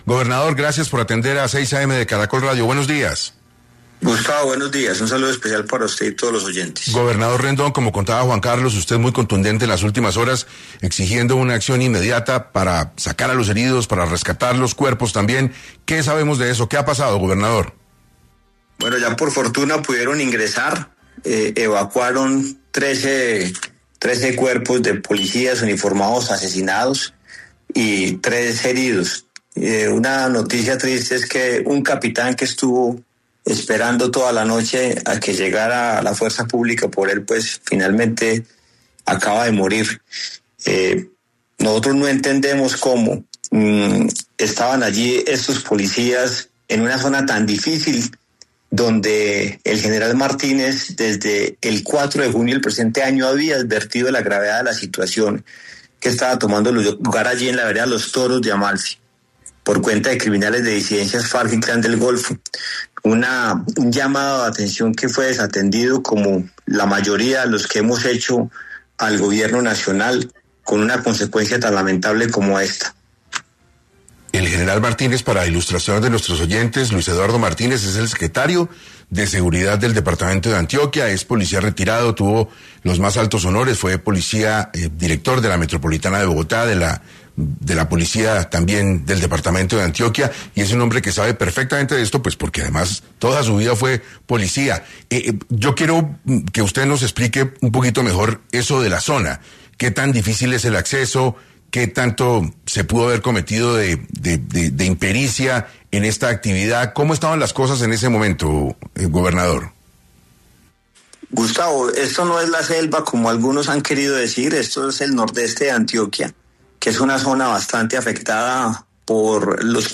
El gobernador Antioquia y el secretario de Seguridad hablaron en 6AM de Caracol Radio y se refirieron a la falta de respuesta del estado frente a las solicitudes de aumentar la asistencia militar en el departamento.